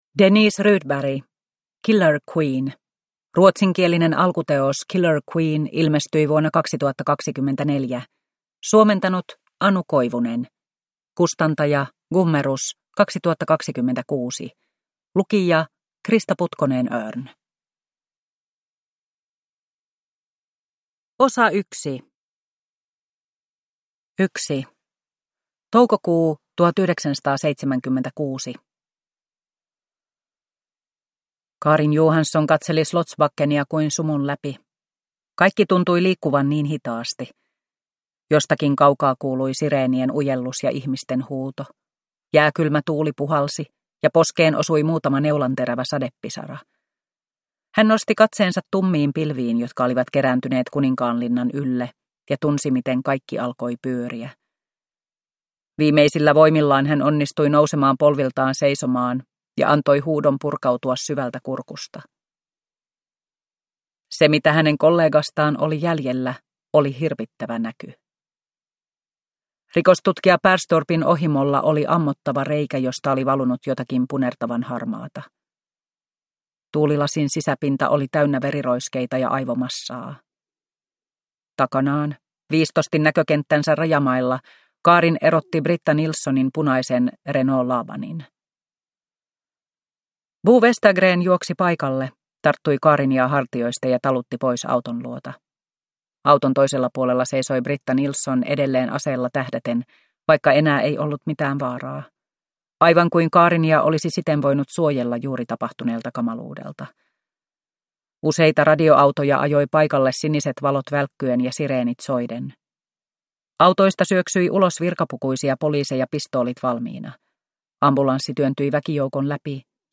Killer Queen – Ljudbok